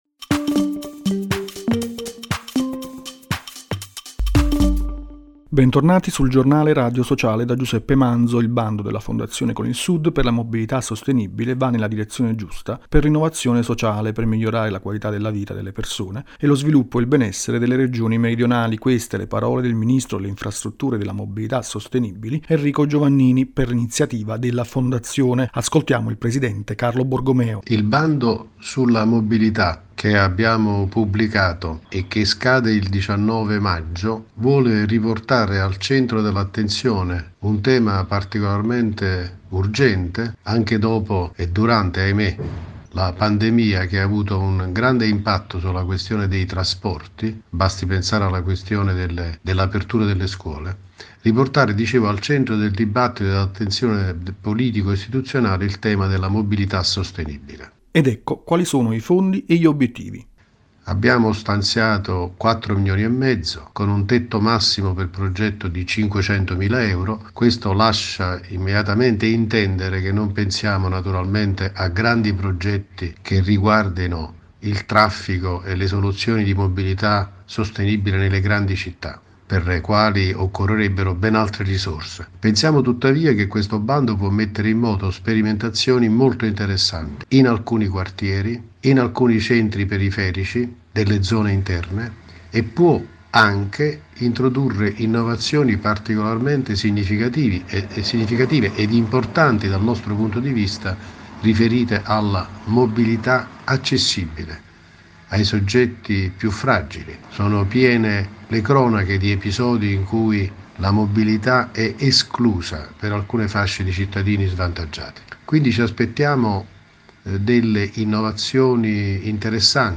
Archivi categoria: Intervista